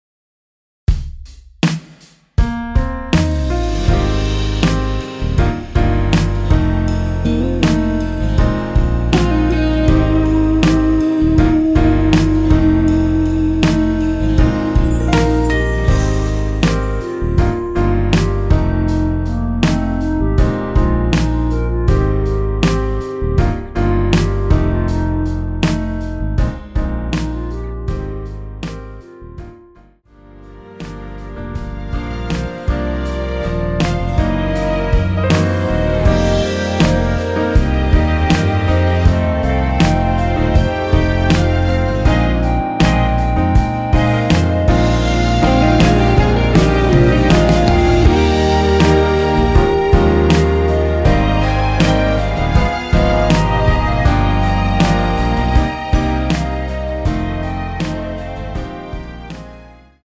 원키에서(-1)내린 멜로디 포함된 MR 입니다.(미리듣기 참조)
Eb
앞부분30초, 뒷부분30초씩 편집해서 올려 드리고 있습니다.